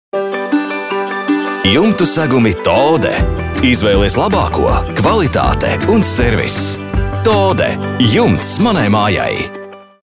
RADIO REKLĀMAS